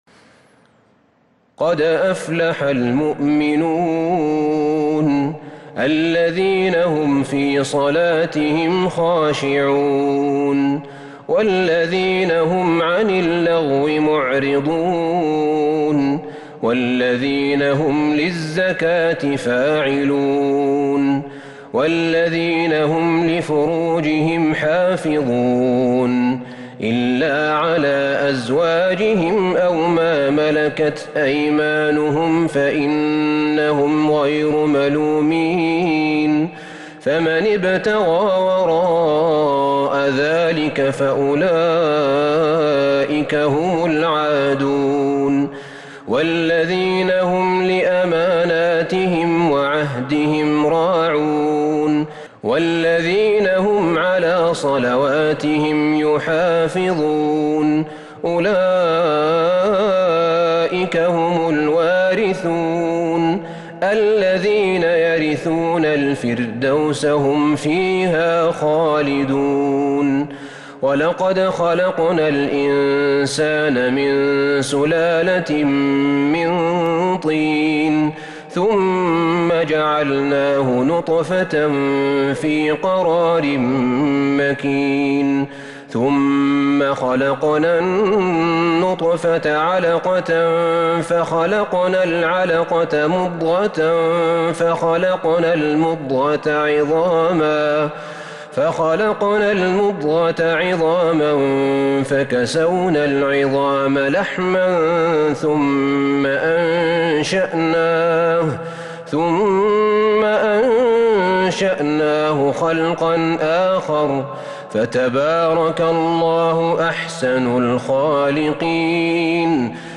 سورة المؤمنون كاملة من تراويح الحرم النبوي 1442هـ > مصحف تراويح الحرم النبوي عام 1442هـ > المصحف - تلاوات الحرمين